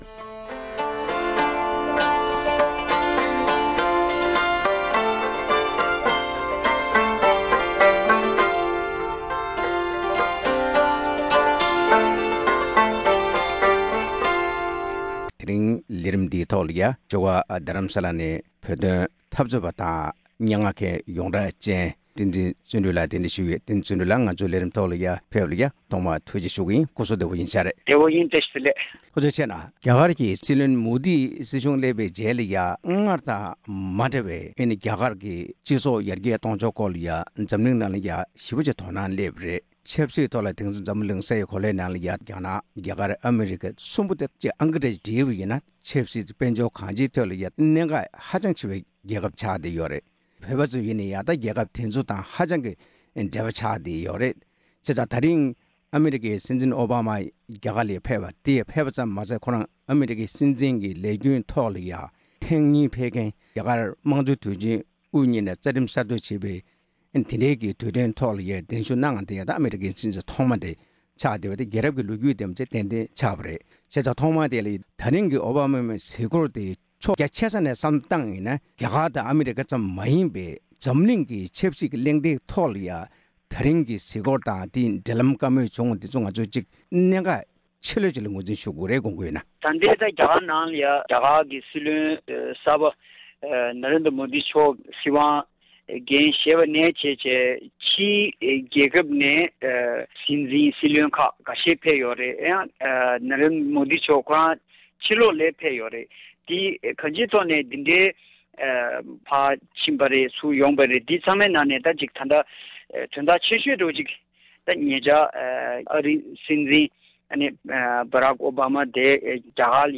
རྒྱ་གར་དང་རྒྱ་ནག་ཨ་རིའི་དབར་གྱི་འབྲེལ་ལམ་སྐོར་གླེང་བ།